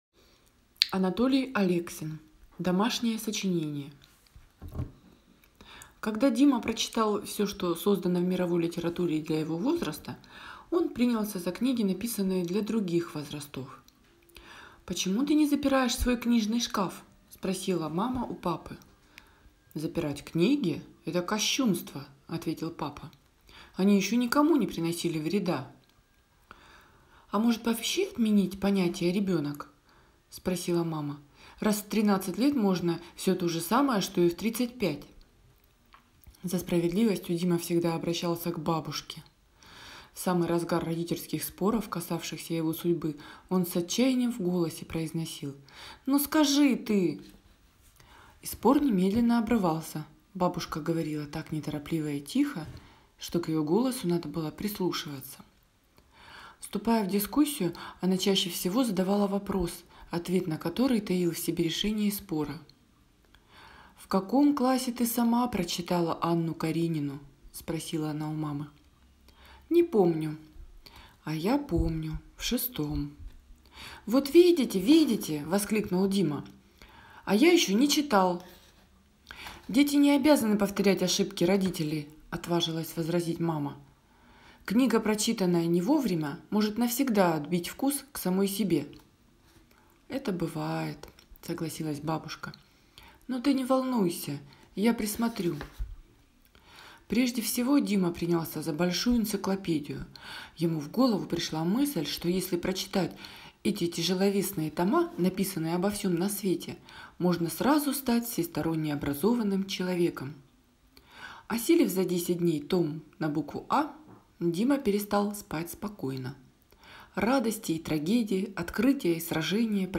Домашнее сочинение - аудио рассказ Алексина - слушать